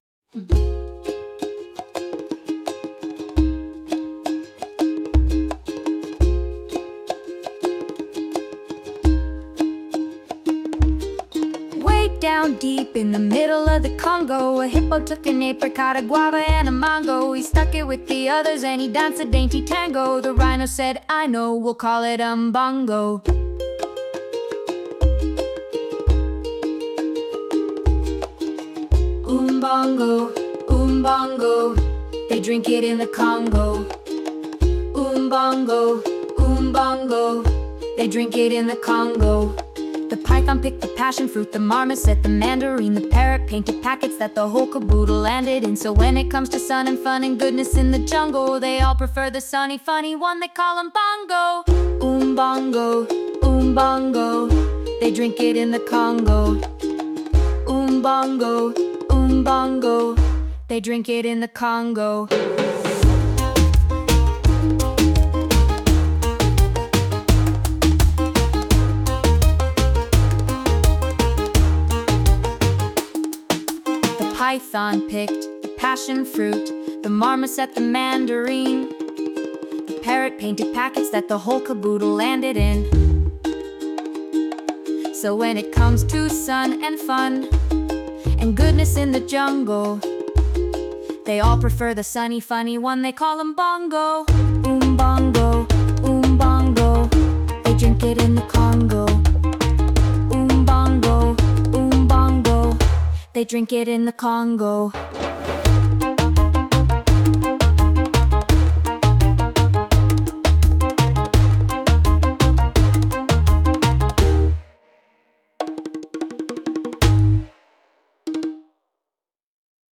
Acoustic, Singer/Songwriter
Nice crisp vocalist, here.